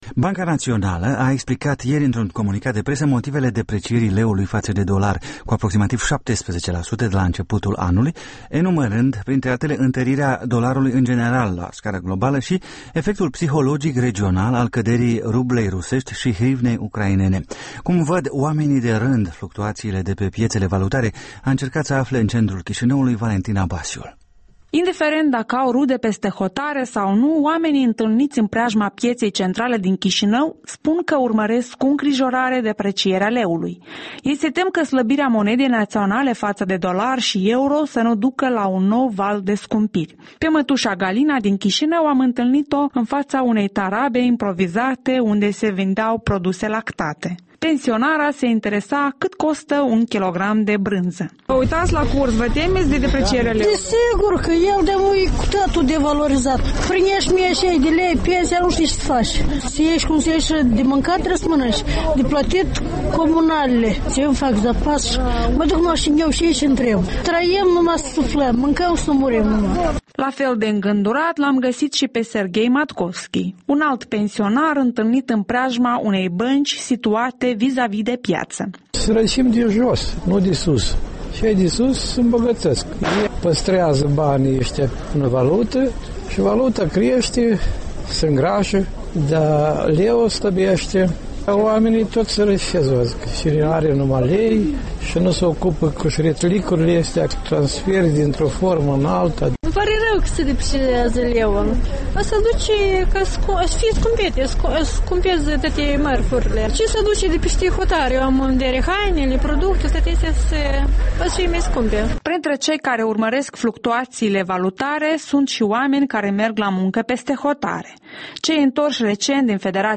pe străzile Chișinăului